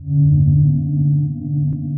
sonarPingAirFar2.ogg